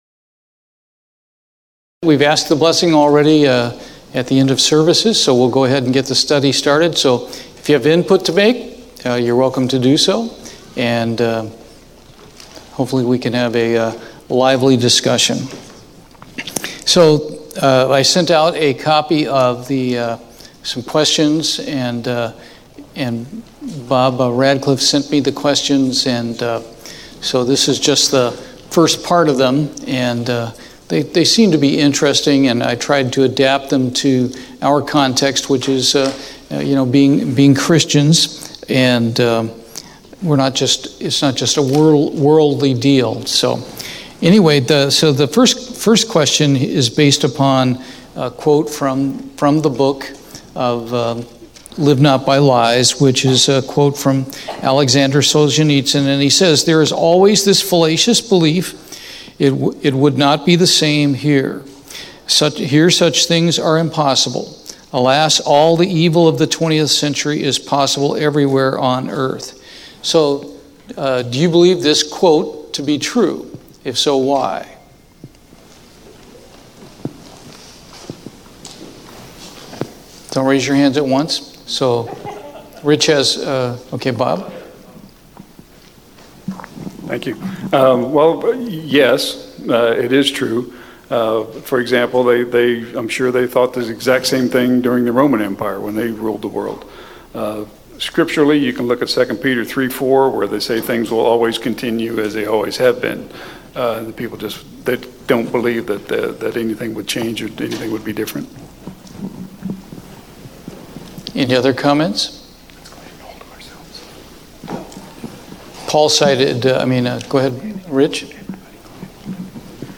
He gave these out to our congregation prior to the study so that members could give answers based on their own personal experiences.